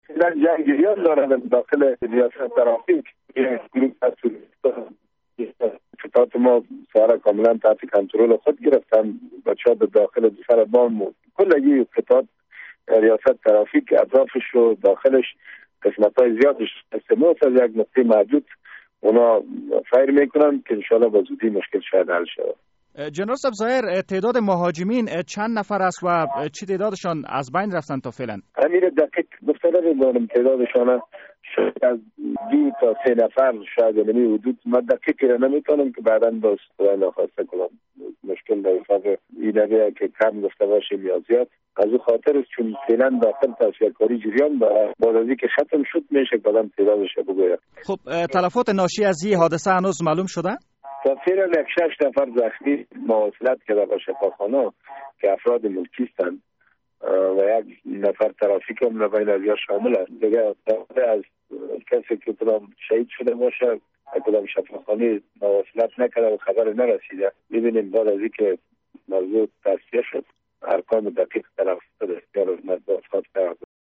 مسوولان امنیتی کابل می گویند، درگیری میان نیرو های امنیتی افغان و مهاجمان مسلح در ریاست ترافیک شهر کابل هنوز هم جریان دارد. جنرال ظاهر رییس تحقیقات جنایی قومندانی امنیه کابل دقایق پیش در مصاحبه با...